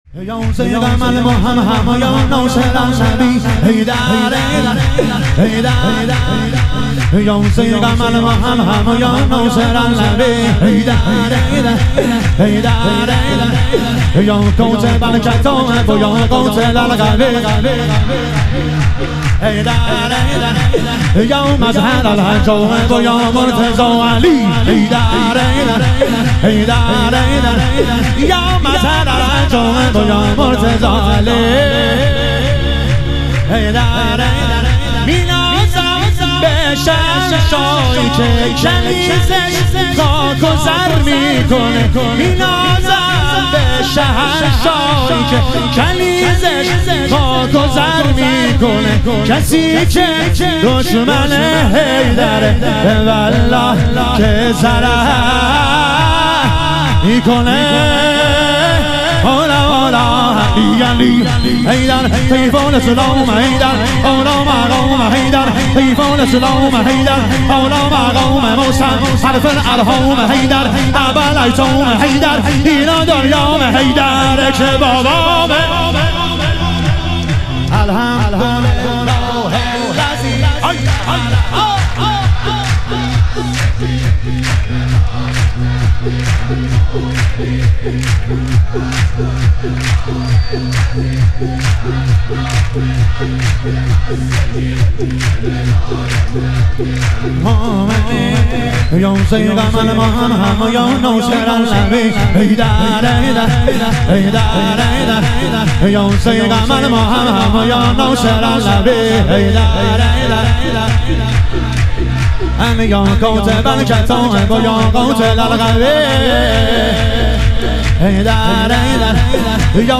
ظهور وجود مقدس حضرت علی اکبر علیه السلام - شور